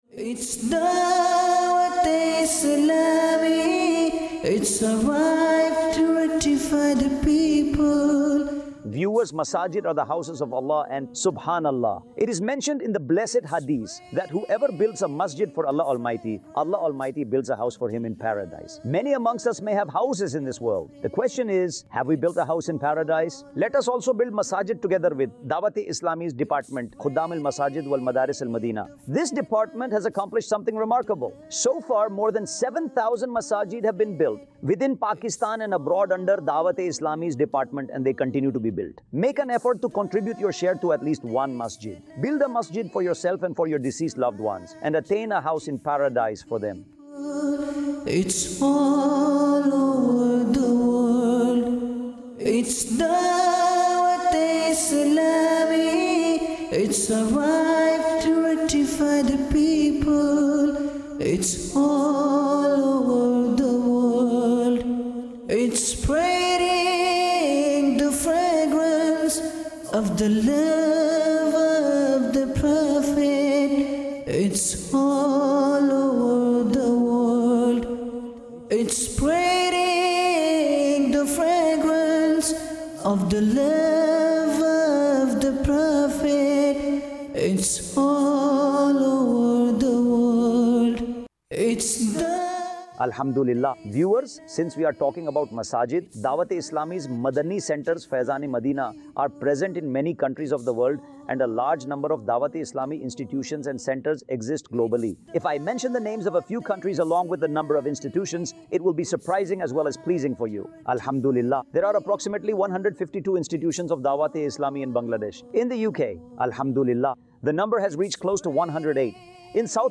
khutba
Khuddam-ul-Masajid Wal Madaris-ul-Madinah | Department of Dawateislami | Documentary 2026 | AI Generated Audio